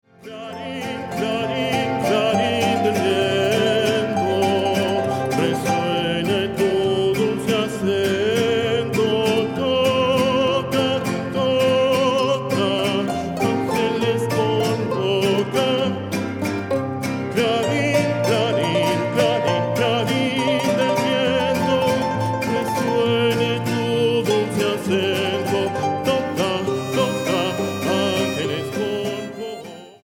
Versiones mariachi